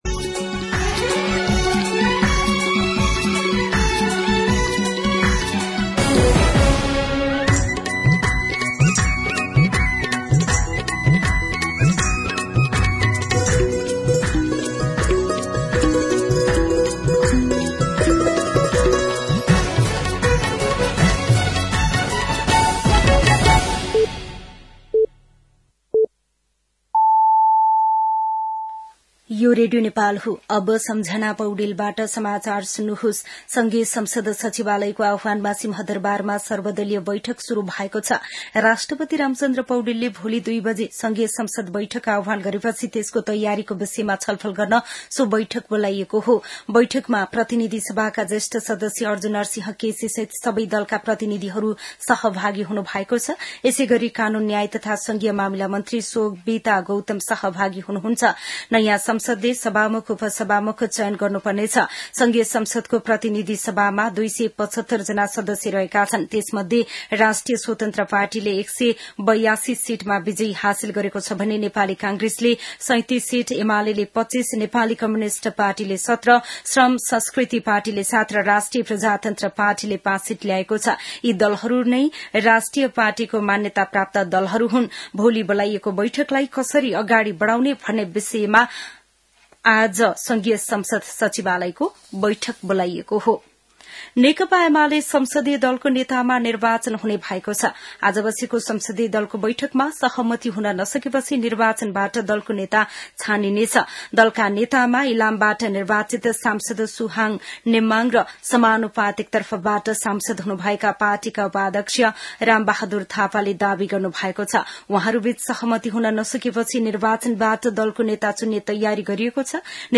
दिउँसो ४ बजेको नेपाली समाचार : १८ चैत , २०८२
4-pm-News-18.mp3